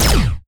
Sci-Fi Effects
weapon_laser_009.wav